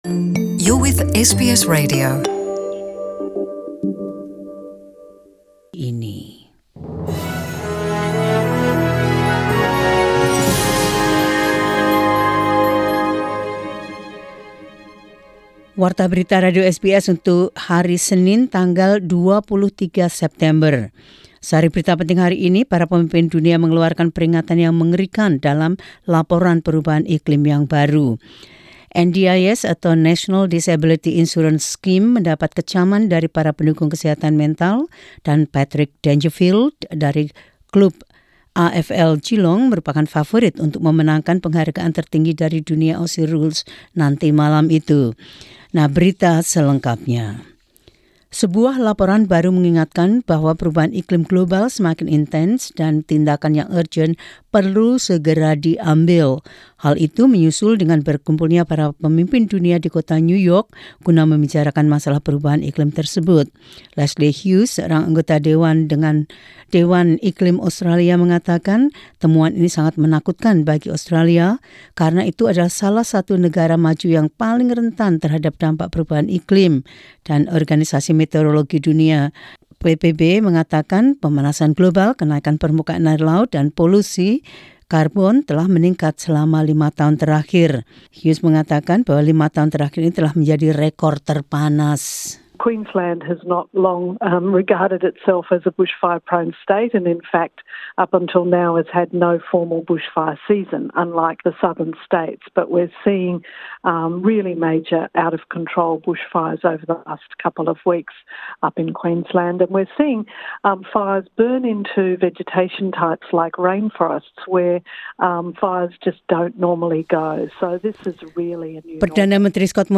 News Bulletin 23 Sep 2019.